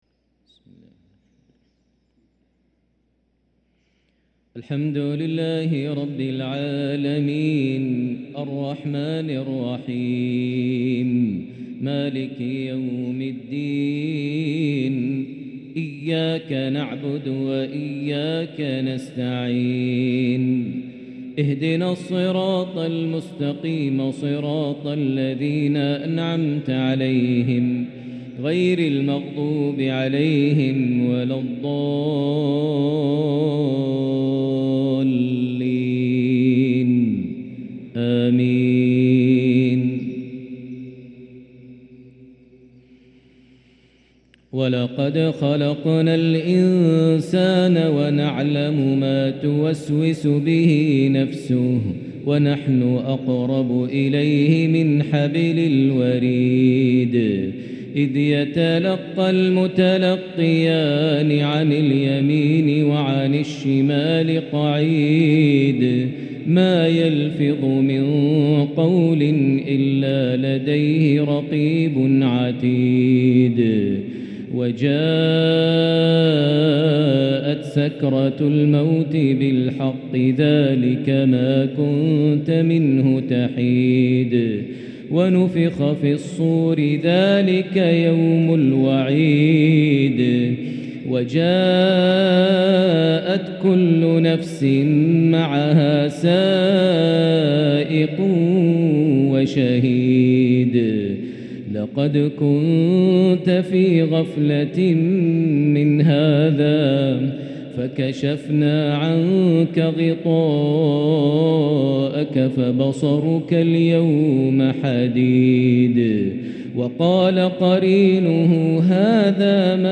تلاوة ماتعة بلمحات بالعجم من سورة ق | مغرب الثلاثاء غرة شعبان ١٤٤٤هـ > 1444 هـ > الفروض - تلاوات ماهر المعيقلي